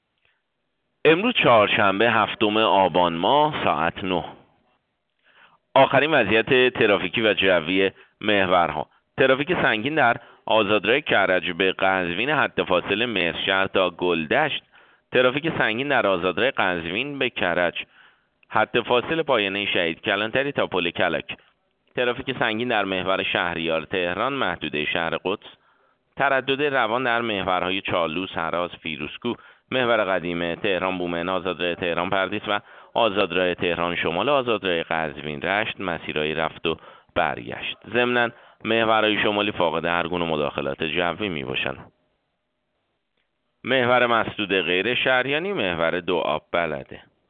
گزارش رادیو اینترنتی از آخرین وضعیت ترافیکی جاده‌ها ساعت ۹ هفتم آبان؛